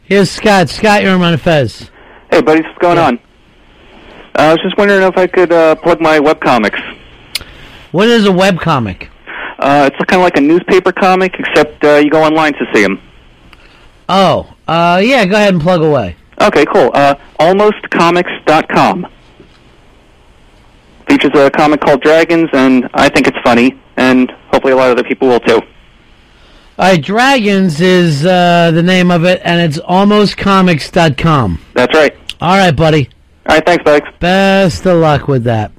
I also managed to sneak in a mention on The Ron & Fez Show. During their "Whattya Want" segment on Tuesday, Ron was nice enough to let me give my site a little plug, which I hope brought a good chunk of people over (I'll know later today).
here to hear my sad, nervous little plug on Ron & Fez.